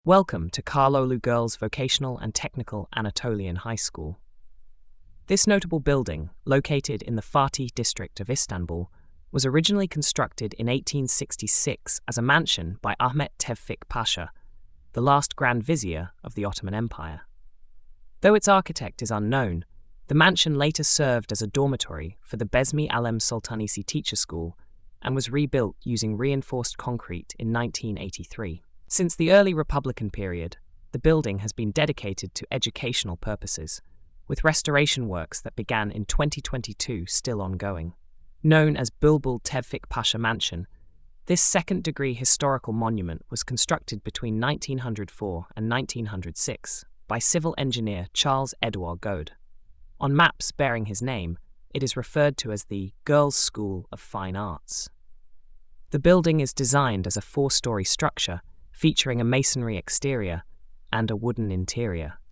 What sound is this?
Audio Narration: